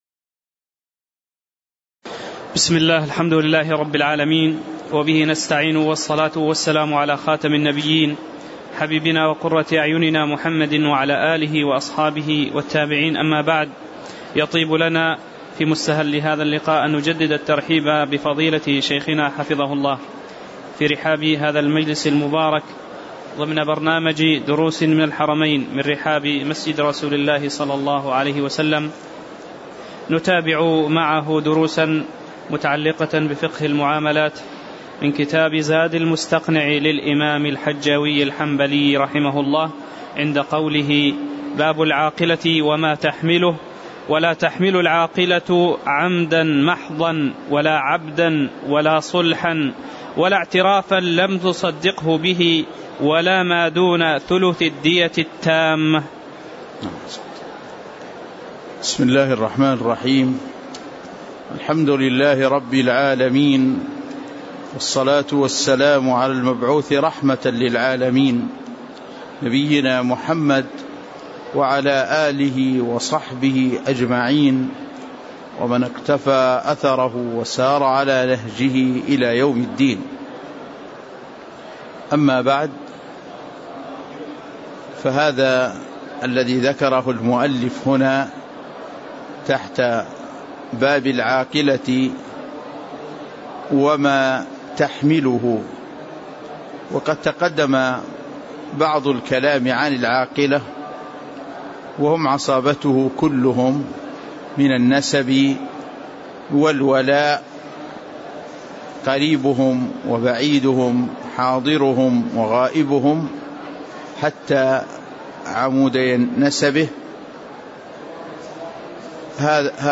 تاريخ النشر ٢٠ ربيع الأول ١٤٣٨ هـ المكان: المسجد النبوي الشيخ